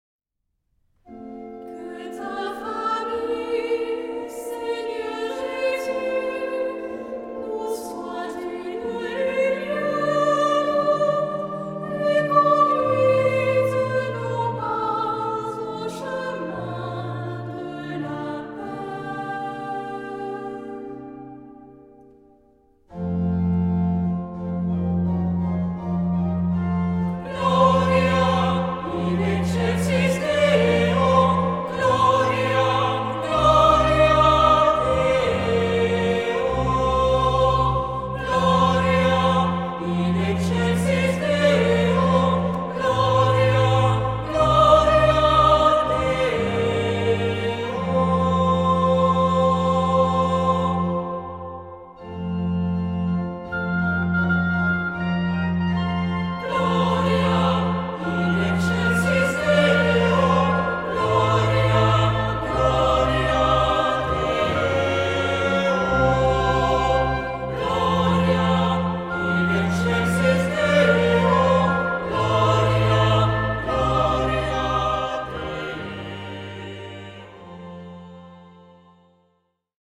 Genre-Stil-Form: Tropar ; Psalmodie
Charakter des Stückes: andächtig
Chorgattung: SAH ODER SATB  (4 gemischter Chor Stimmen )
Instrumente: Orgel (1) ; Melodieinstrument (ad lib)
Tonart(en): F-Dur